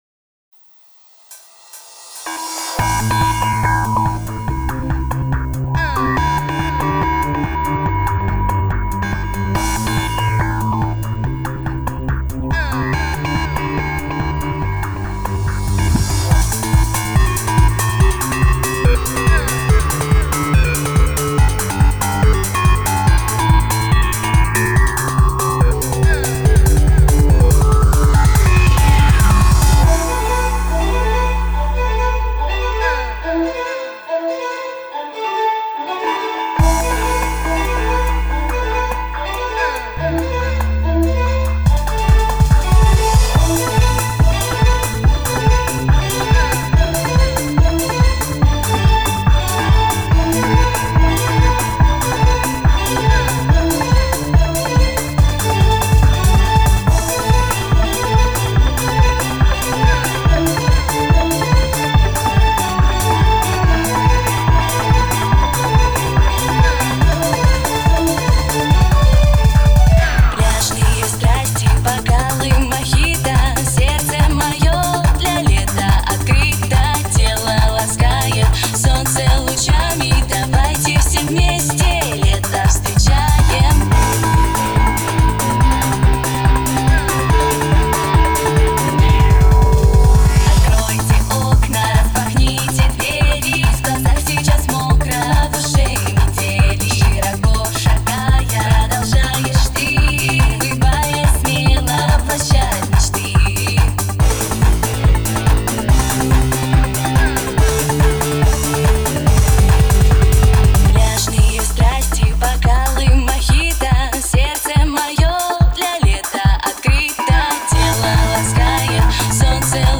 Танцевальный трек с оркестром.